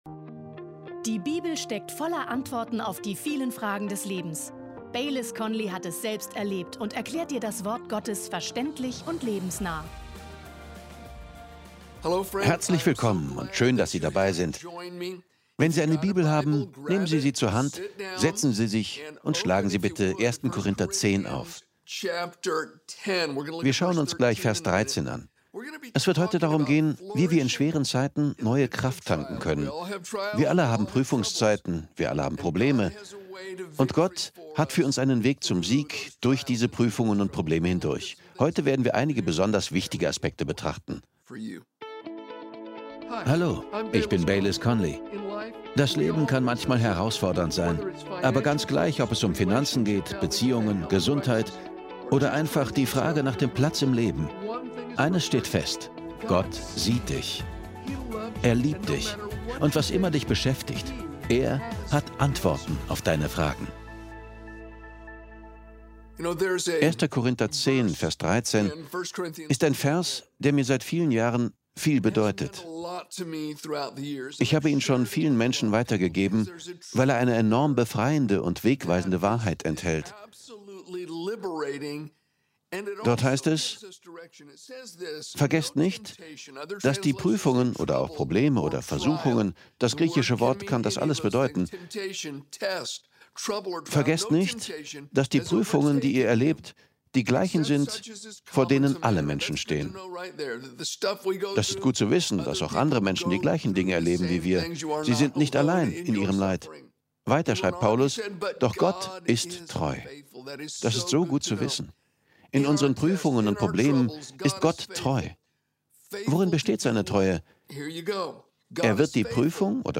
Beschreibung vor 2 Jahren Machst du gerade eine schwere Zeit durch und siehst keinen Ausweg? Dann sei dir bewusst, dass womöglich ein geistlicher Kampf um dich tobt. Aber du kannst durch die Kraft Jesu bestehen und die Krise überwinden – wie das geht, erfährst du in dieser Predigt.